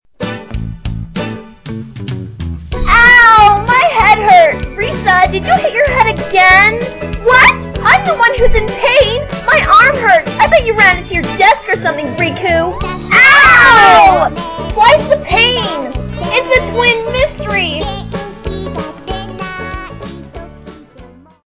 It was just a random spurt of energy that I had and it ended up being one that I added music to ^_^ (Music: chibiusa from Sailor Moon) Yeup! If you don't know, they're supposed to sound somewhat alike cause they're twins, but not totally. I tried to get Risa's voice just a little bit higher since she's a lot more girly than Riku is. The script is from the end of volume 7 of the manga, a short 4 panel comic.